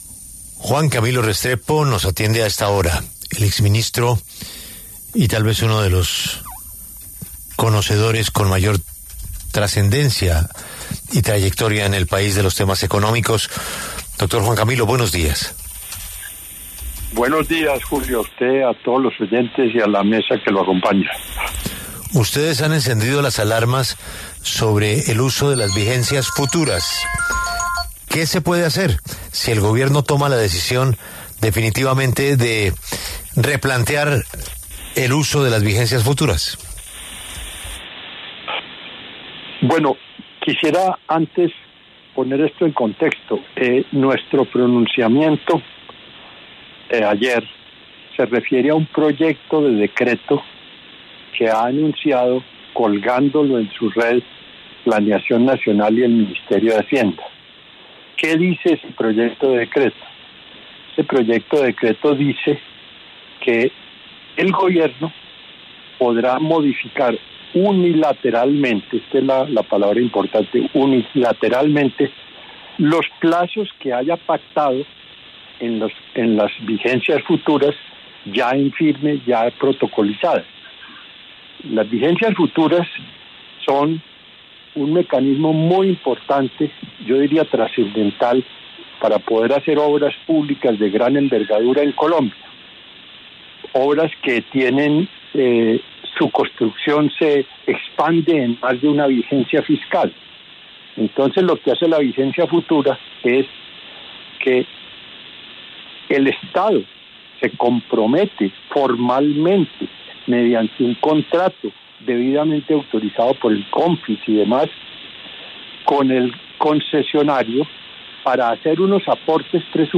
El exministro de Hacienda Juan Camilo Restrepo pasó por los micrófonos de La W para hablar sobre el proyecto de decreto del Gobierno Petro que modificaría las vigencias futuras, algo que calificó como un “atentado a la seguridad jurídica”.